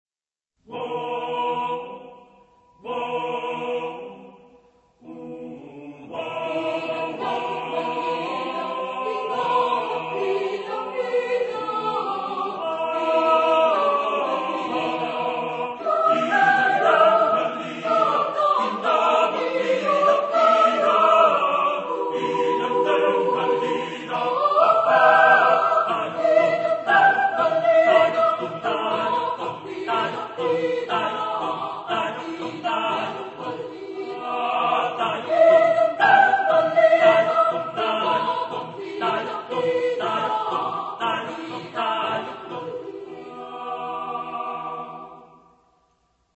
Chorgattung: SATB  (4 gemischter Chor Stimmen )